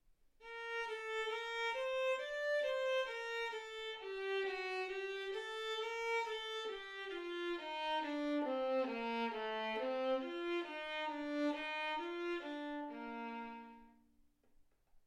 Hegedű etűdök Kategóriák Klasszikus zene Felvétel hossza 00:15 Felvétel dátuma 2025. december 8.